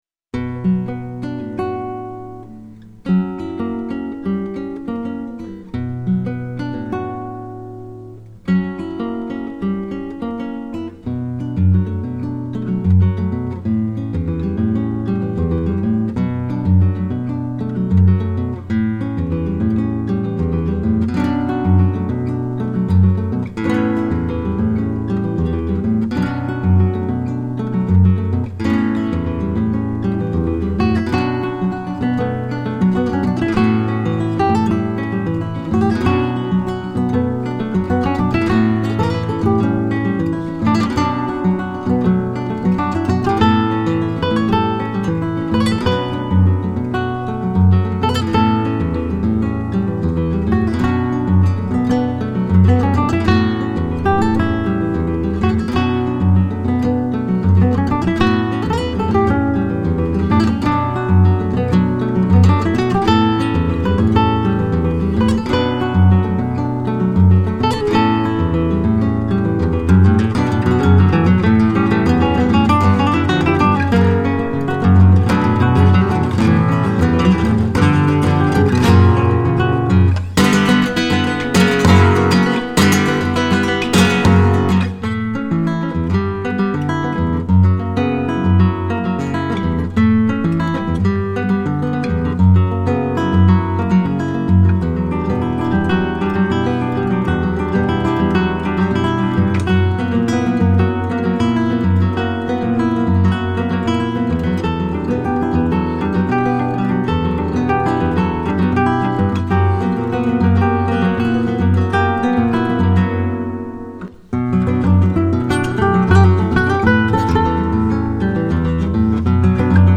Original works for classical and acoustic guitar